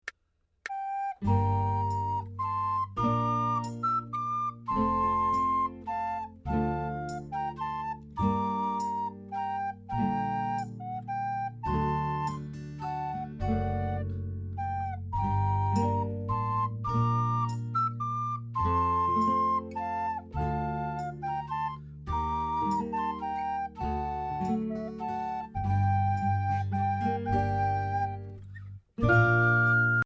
Voicing: Soprano Re